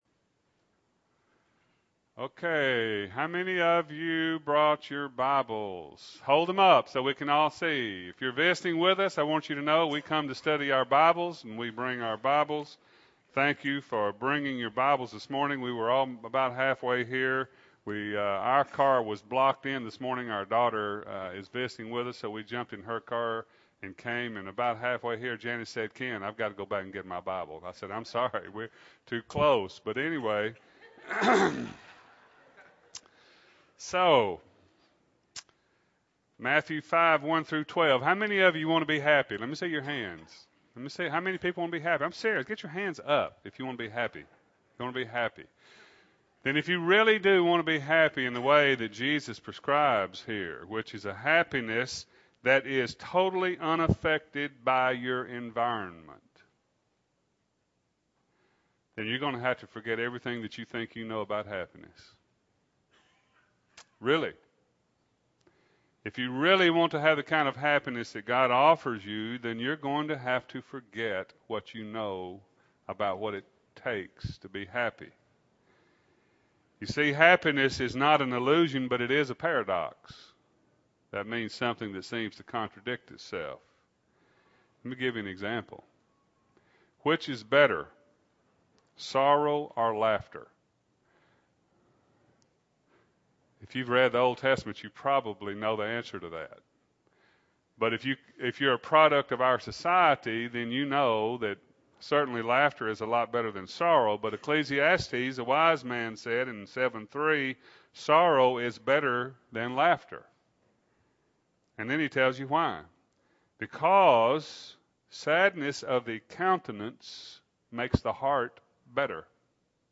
2008-02-10 – Sunday AM Sermon – Bible Lesson Recording